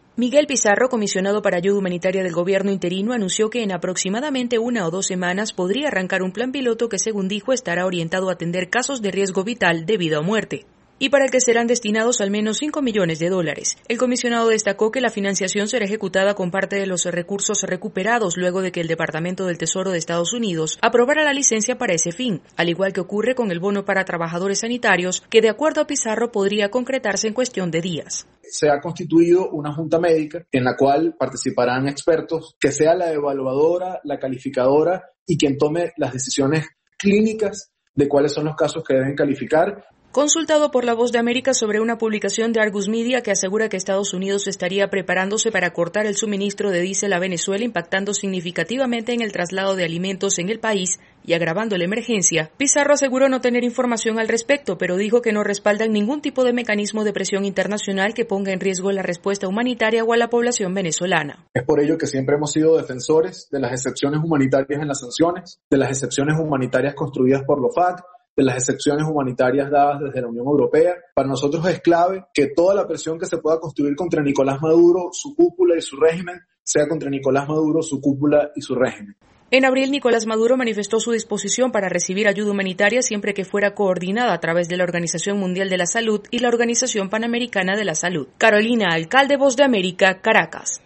Venezuela: Informe ayuda humanitaria